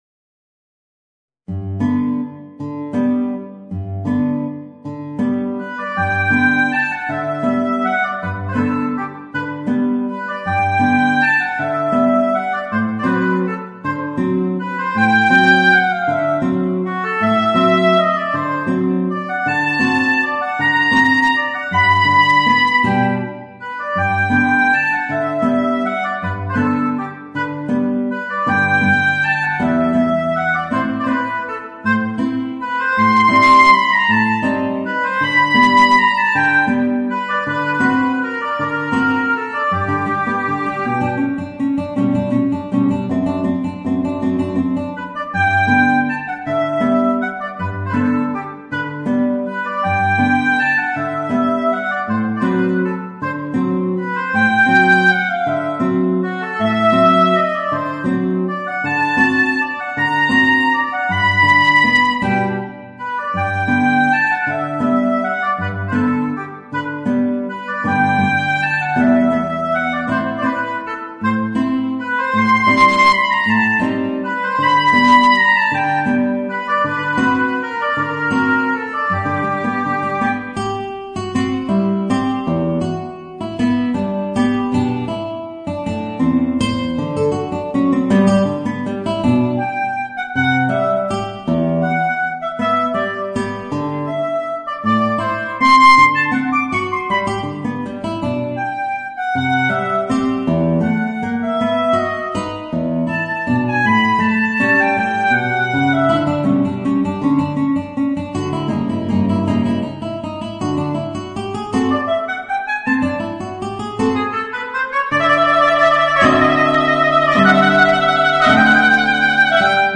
Voicing: Guitar and Oboe